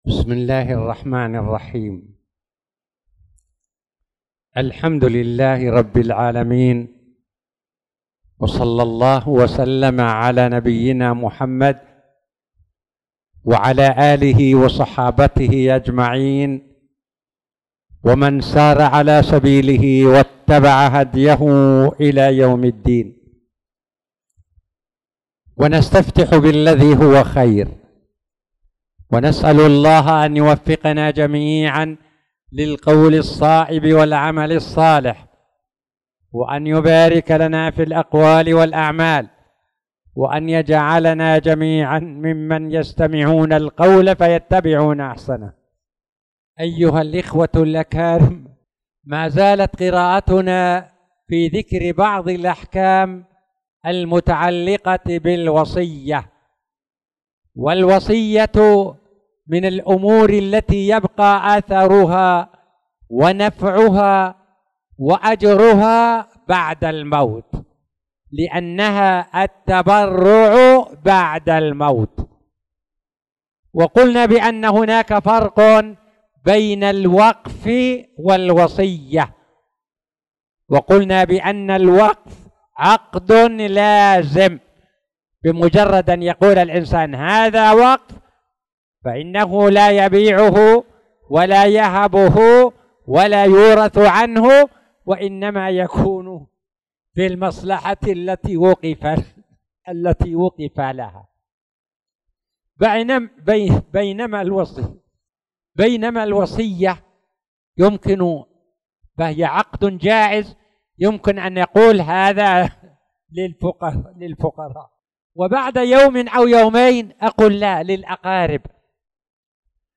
تاريخ النشر ٢٢ شعبان ١٤٣٧ هـ المكان: المسجد الحرام الشيخ